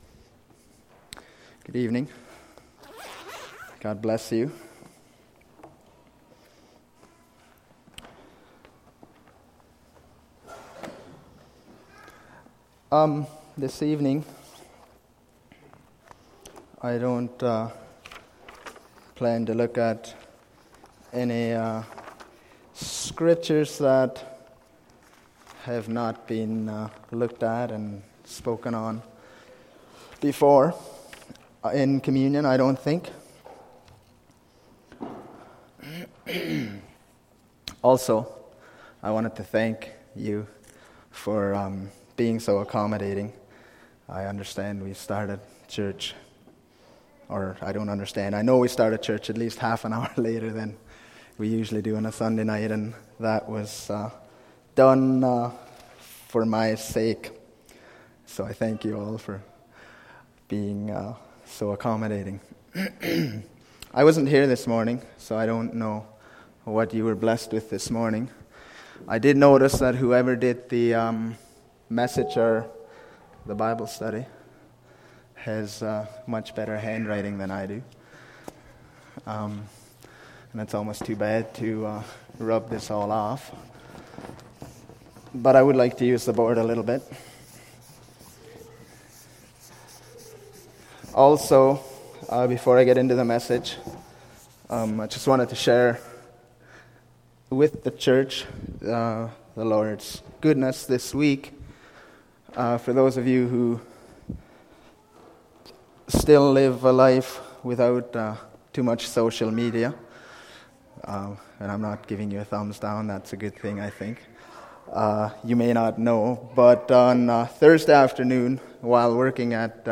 Service Type: Saturday Evening